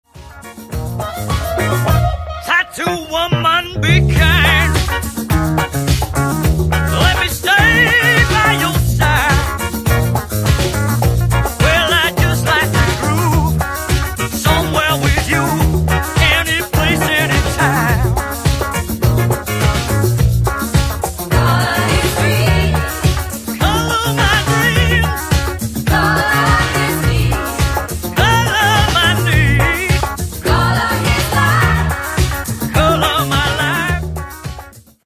Genere:   Afro | Funk